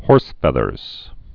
(hôrsfĕthərs) Slang